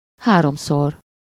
Ääntäminen
IPA : /θraɪs/ US : IPA : [θraɪs]